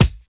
KICK HIT.wav